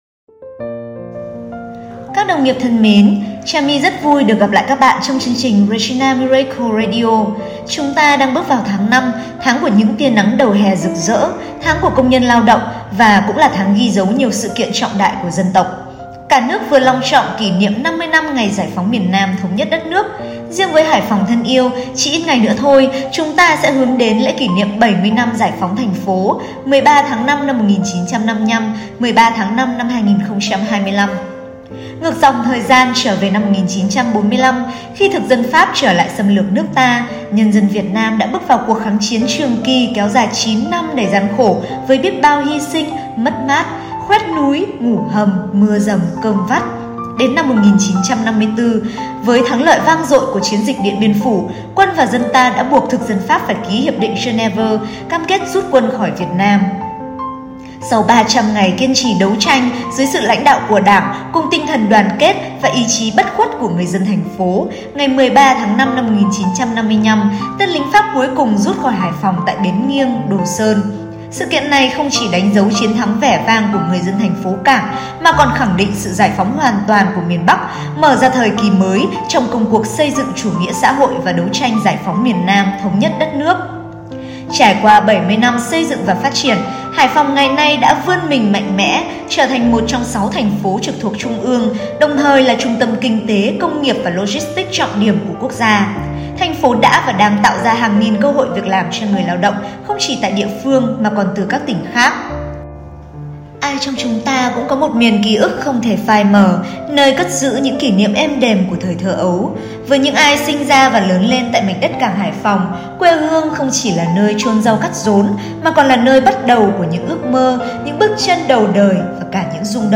những ca khúc quê hương
những bản nhạc hào hùng
những giai điệu sẻ chia và tiếp thêm nghị lực